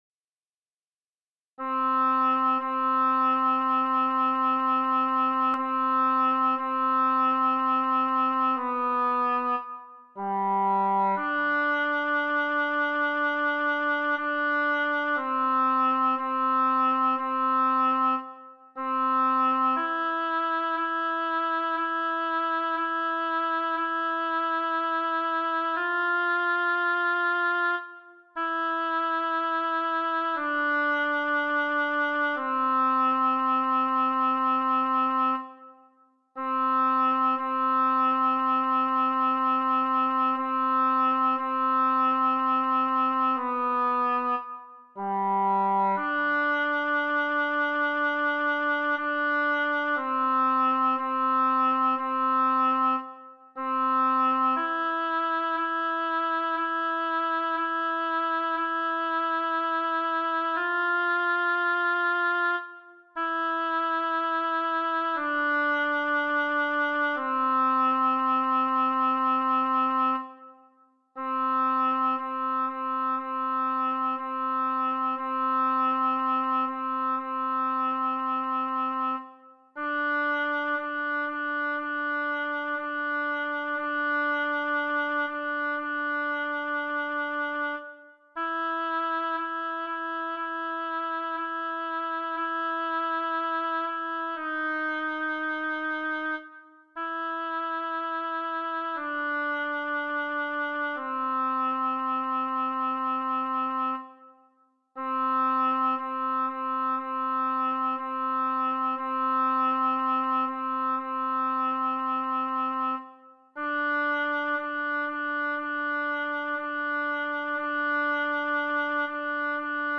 Mp3 tie bie paoim voix ALTI    Tibie paiom alto